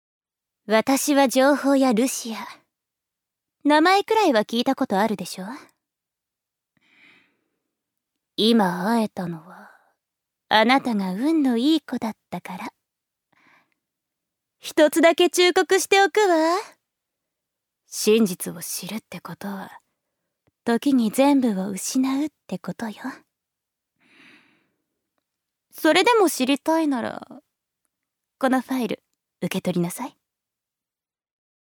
ジュニア：女性
セリフ５